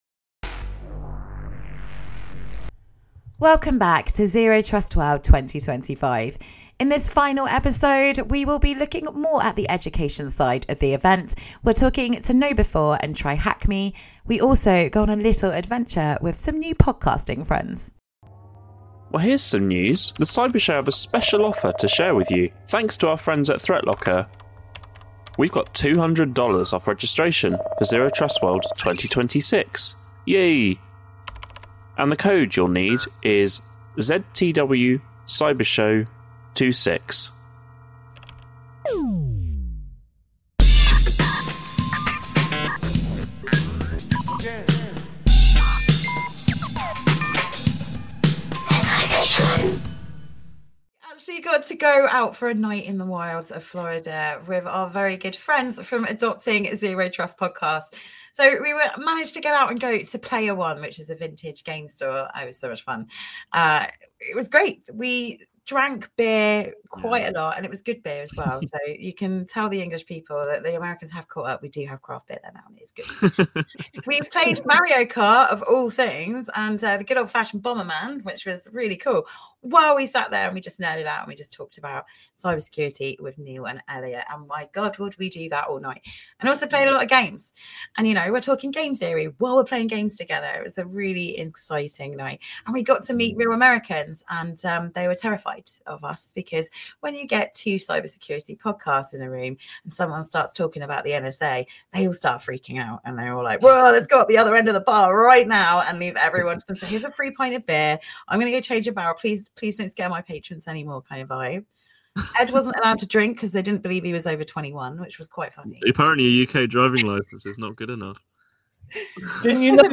Categories: Audio Only In The Chair Interview Free Open Source Software Health and Technology Privacy, Dignity, Personal Data Distributed and Federated Systems Science, Research, Research Methods